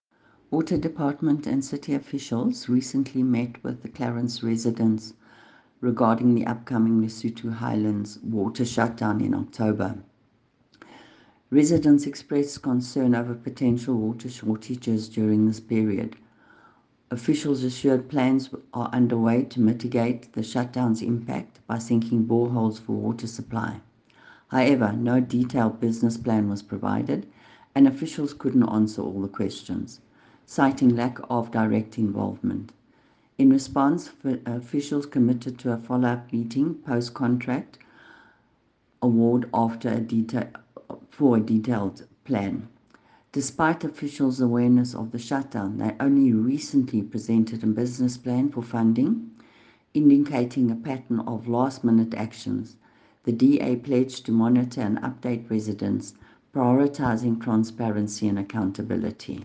English soundbite by Cllr Irene Rugheimer.